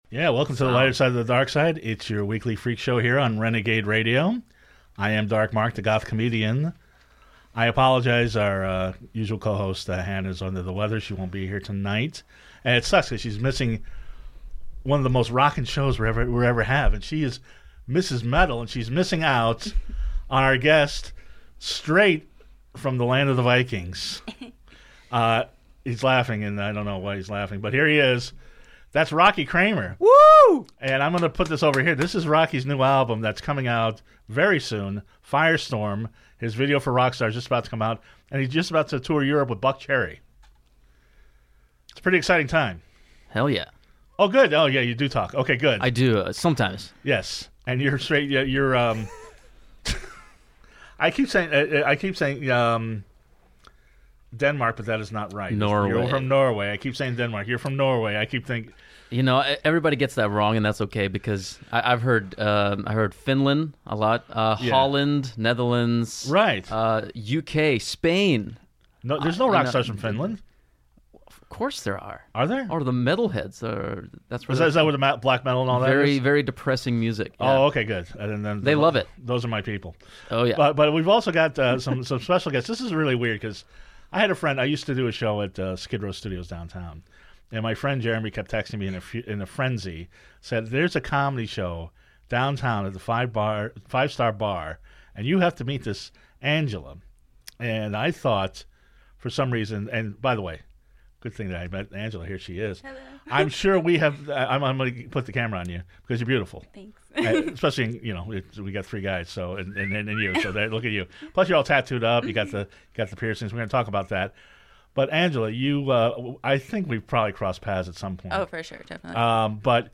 in his signature deadpan delivery